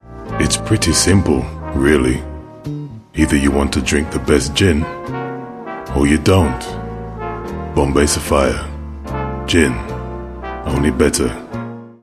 20s-30s. Male. Black British.